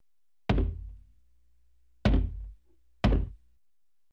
Удары плечом в дверь
Тут вы можете прослушать онлайн и скачать бесплатно аудио запись из категории «Двери, окна».